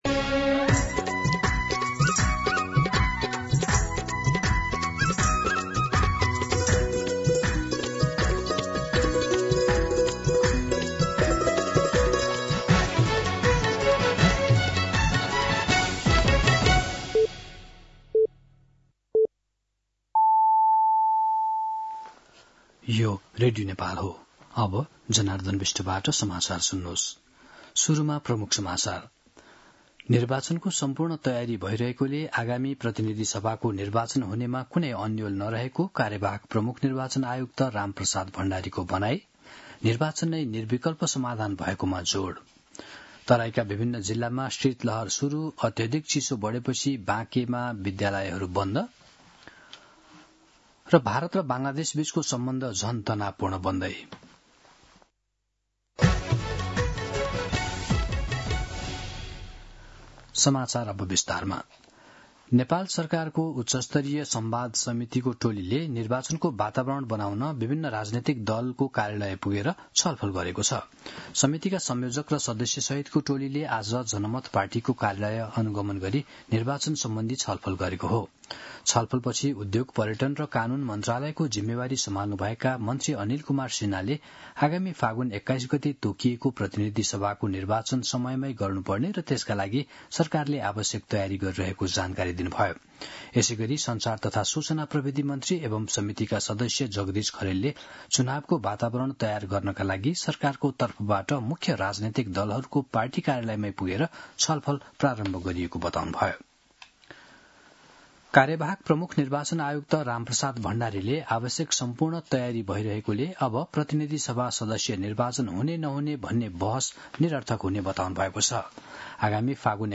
दिउँसो ३ बजेको नेपाली समाचार : ९ पुष , २०८२
3-pm-Nepali-News-3.mp3